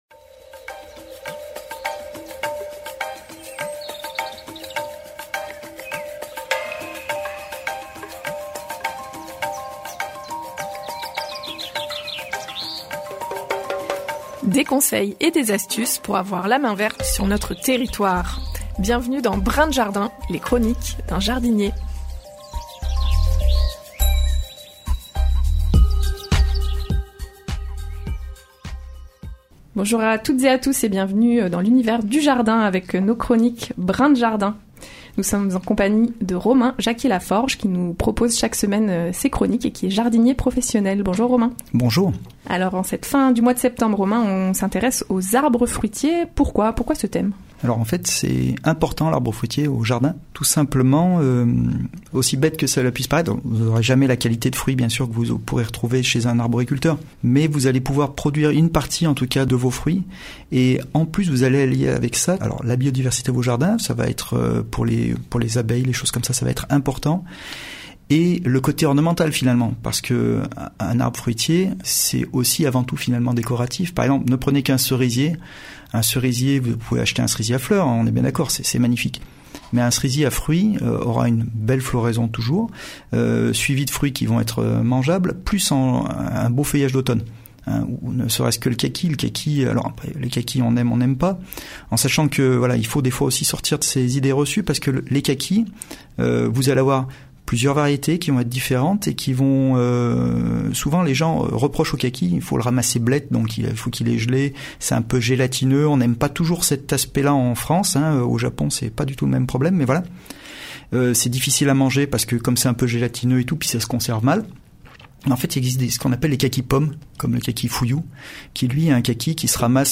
La nouvelle chronique hebdomadaire sur les ondes de Radio Royans Vercors